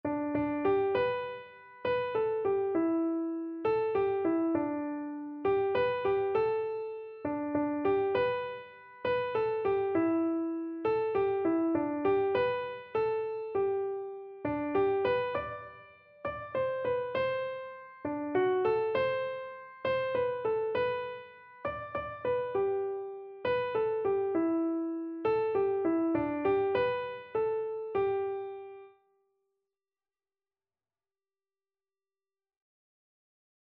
Christian Christian Keyboard Sheet Music Higher Ground
Free Sheet music for Keyboard (Melody and Chords)
3/4 (View more 3/4 Music)
G major (Sounding Pitch) (View more G major Music for Keyboard )
Keyboard  (View more Easy Keyboard Music)
Classical (View more Classical Keyboard Music)